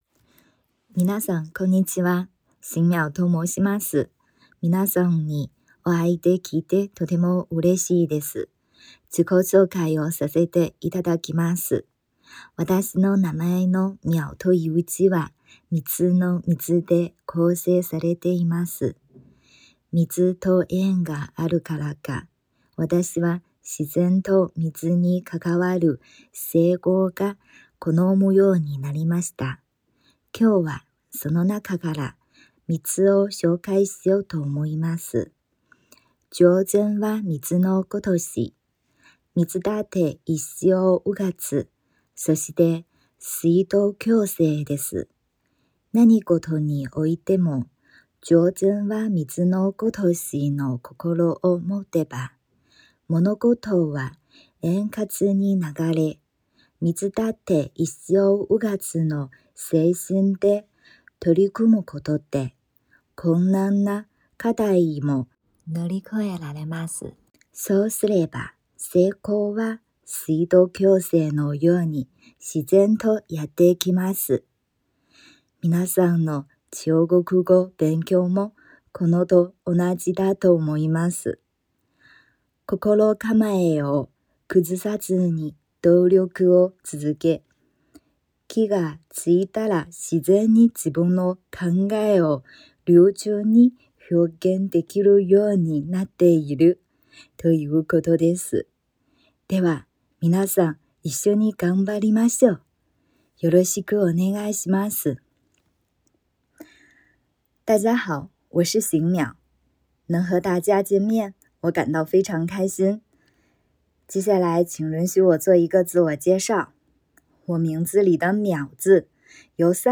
自己紹介：